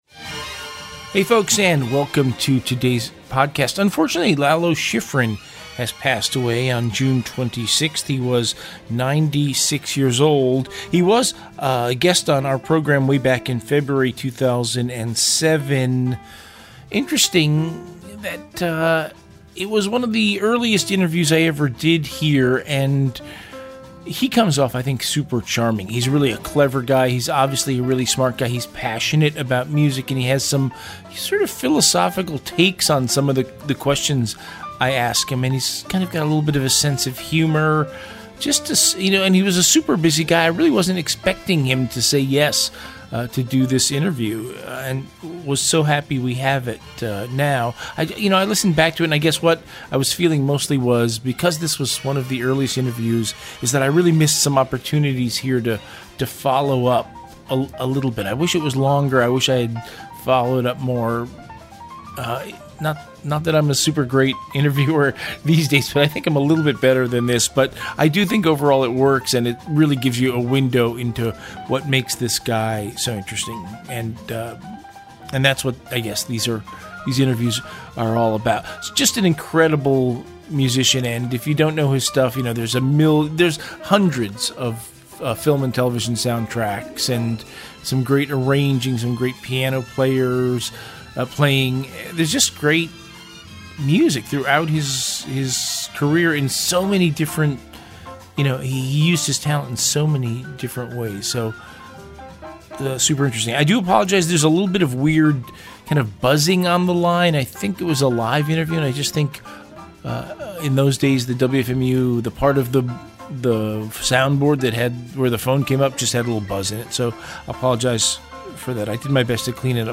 Talkshow
Interviews